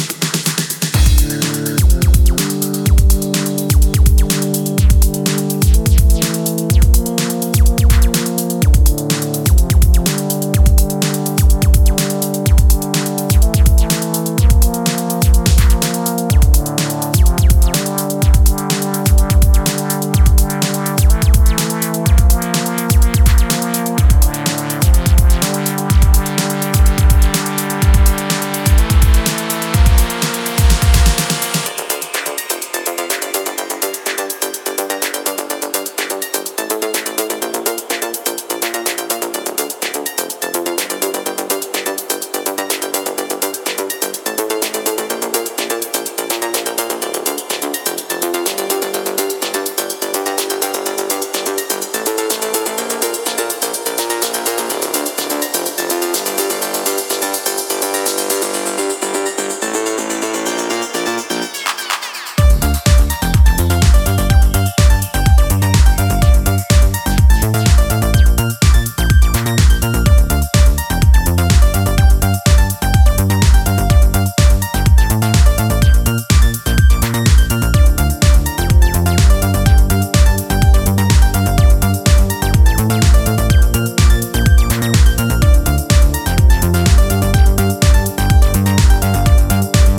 is brimming with retro synths and digi stabs on the original